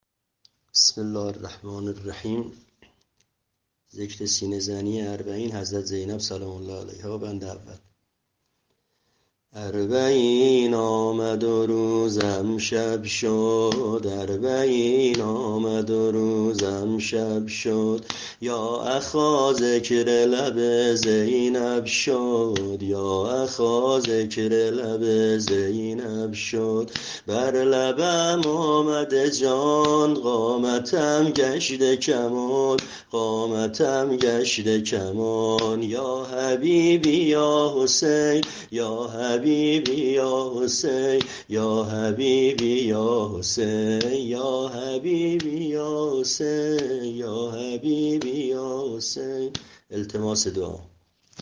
عنوان : سبک سینه زنی اربعین